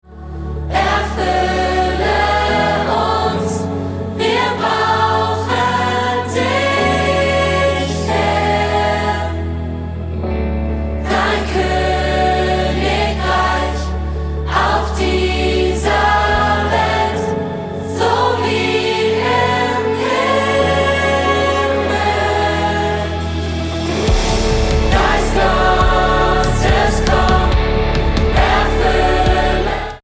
100 Sänger und Live-Band